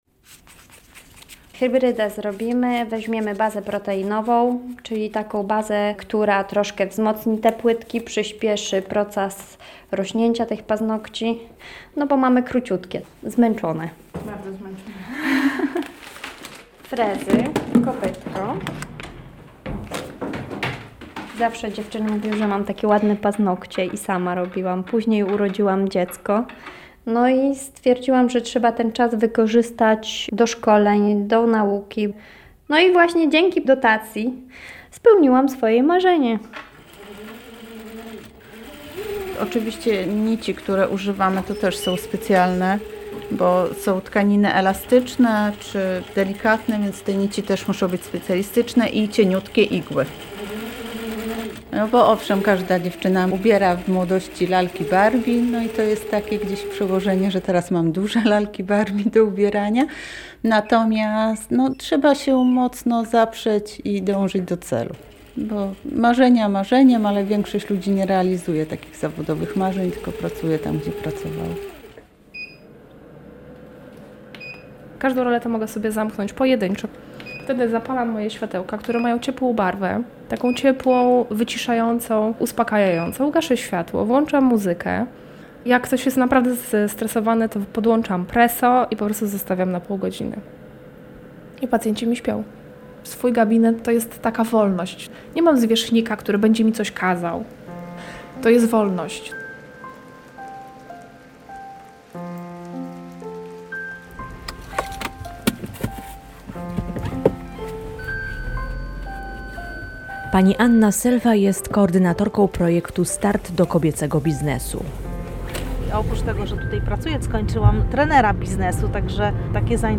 KALEJDOSKOP REGIONALNY Reportaż